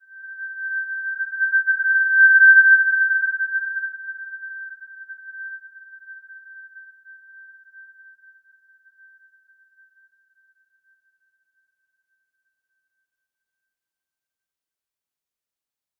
Simple-Glow-G6-p.wav